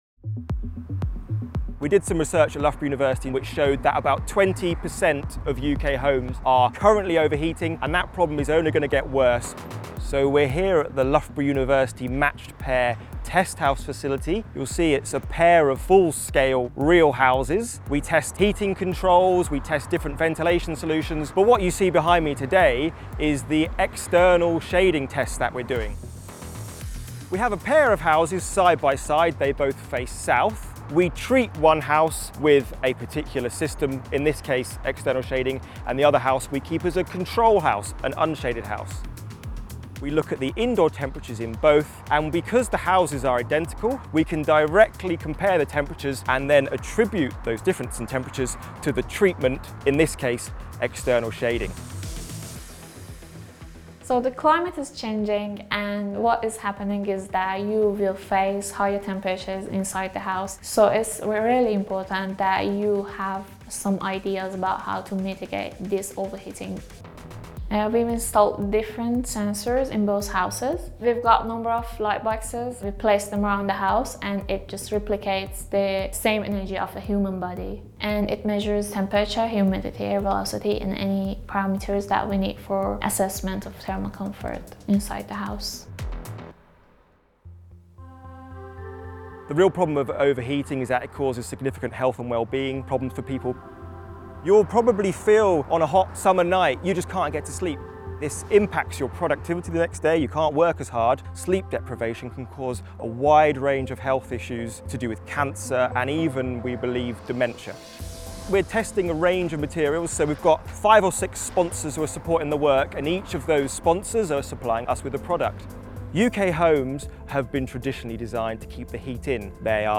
Audio_Test+House+Heatwaves_Full+with+Music.mp3